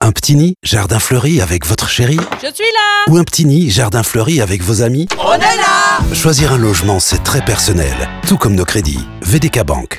vdk Bank-Woonkrediet-radio-FR-10s.wav